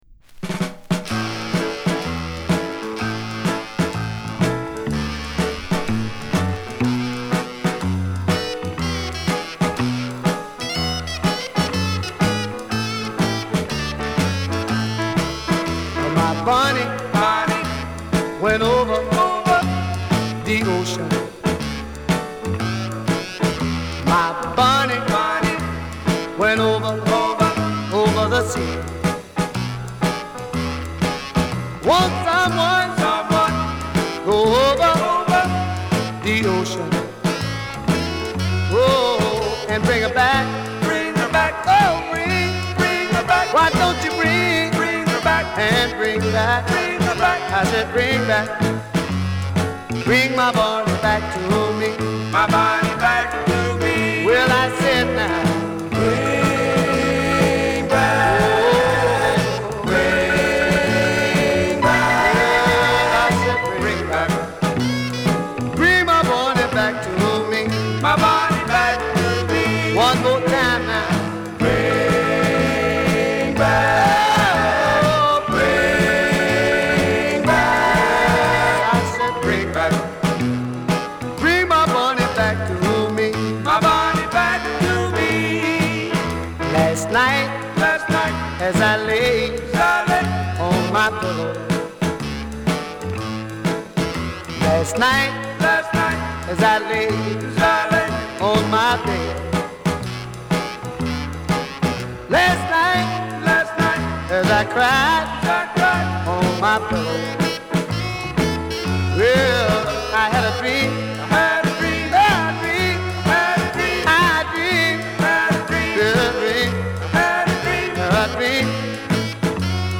B面はソウリッシュなガレージ・ナンバー。